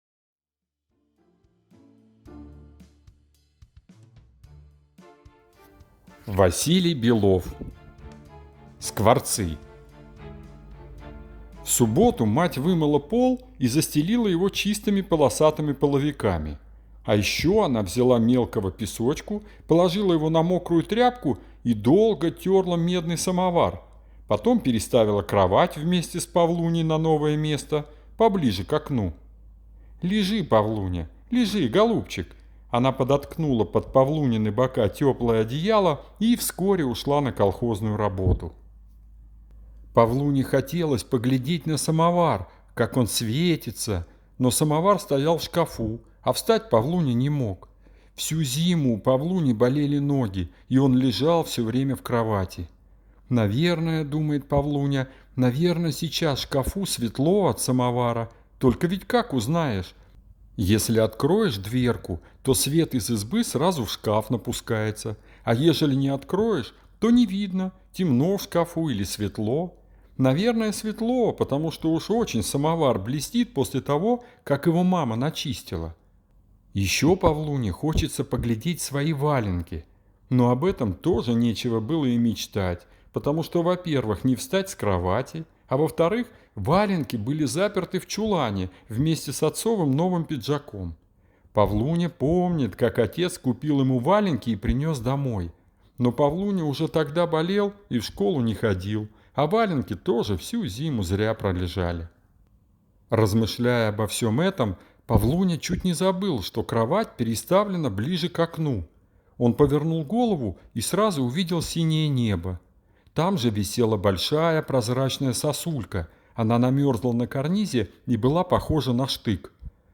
Скворцы - аудио рассказ Белова - слушать онлайн